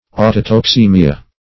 Meaning of autotoxaemia. autotoxaemia synonyms, pronunciation, spelling and more from Free Dictionary.